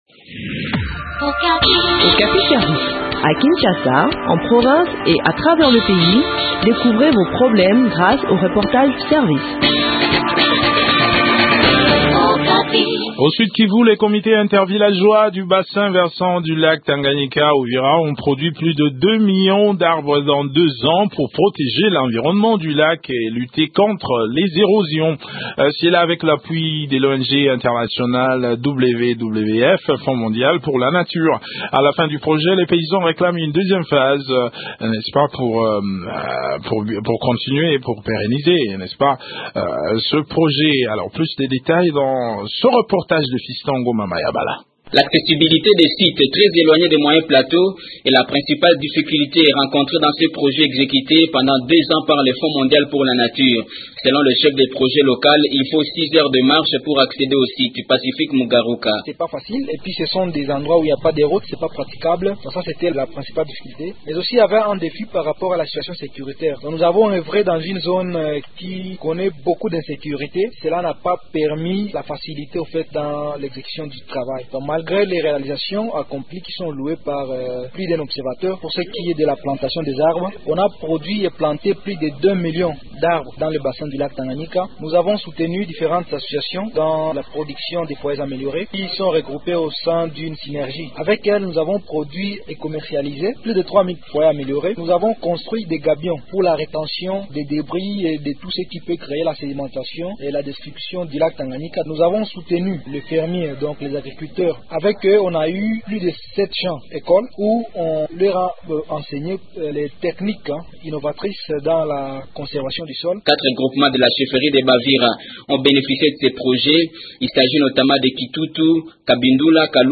s’est entretenu avec